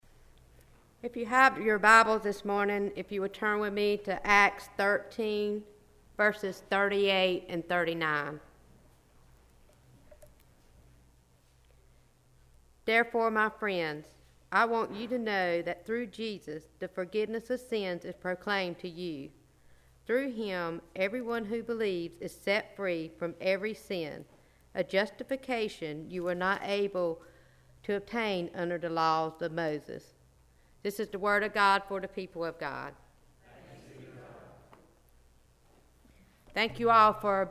Worship Service 8-27-17
This weeks scripture and message: Scripture: Acts 13: 38-39 Message